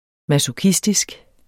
Udtale [ masoˈkisdisg ]